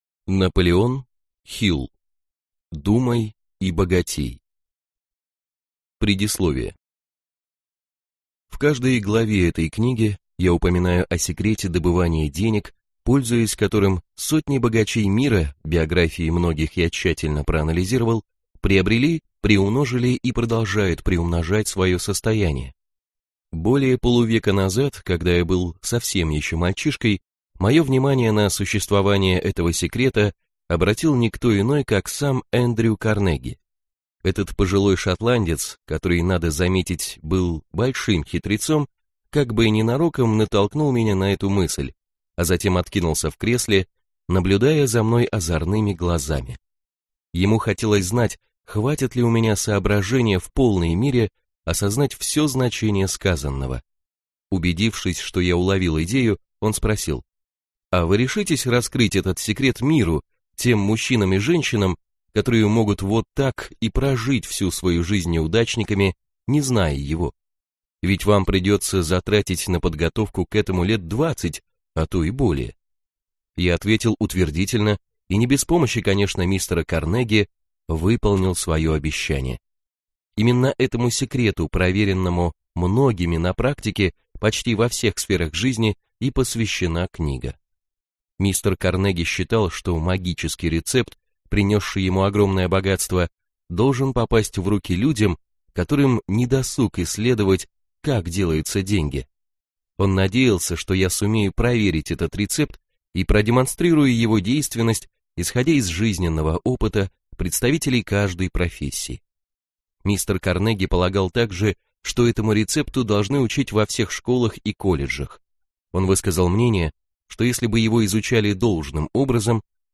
Аудиокнига Думай и богатей | Библиотека аудиокниг